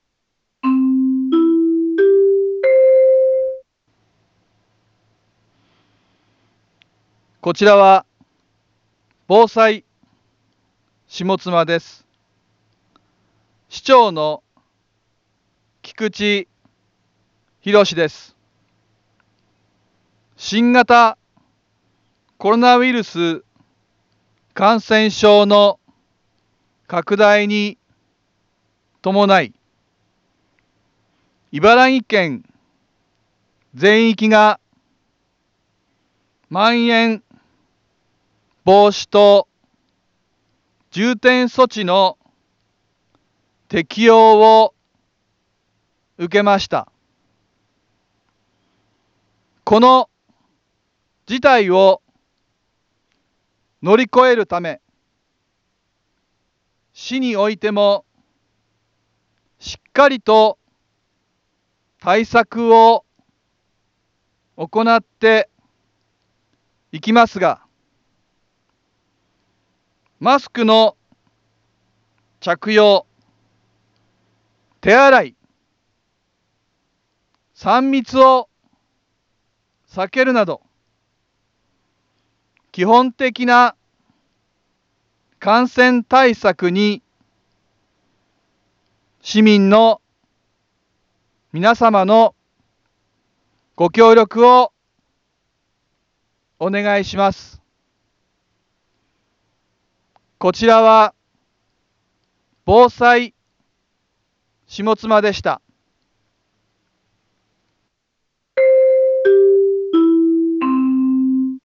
Back Home 一般放送情報 音声放送 再生 一般放送情報 登録日時：2022-01-30 18:31:55 タイトル：新型コロナウイルス感染症にかかる注意喚起 インフォメーション：こちらは、防災下妻です。 市長の菊池博です。